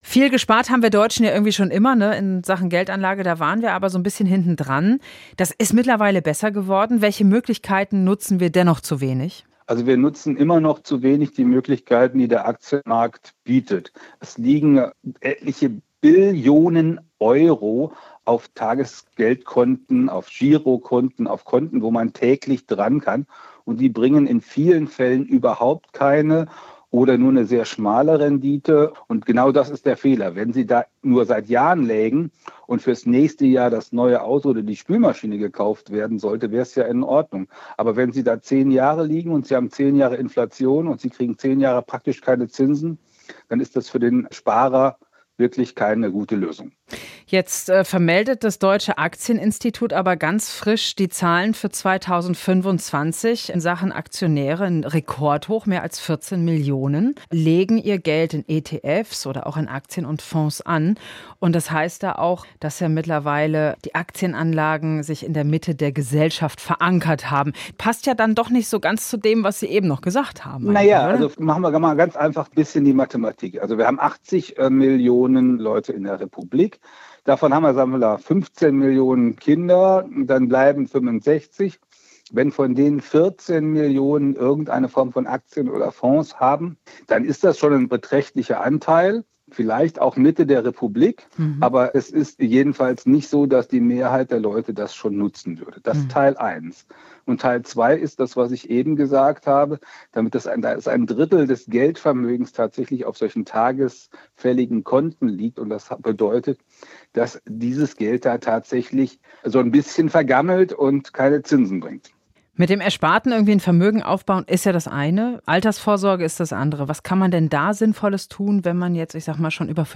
Wir haben mit Hermann-Josef Tenhagen von Finanztip darüber gesprochen, warum es sinnvoll ist, Geld in Aktien, ETFs oder Fonds anzulegen – auch im Alter.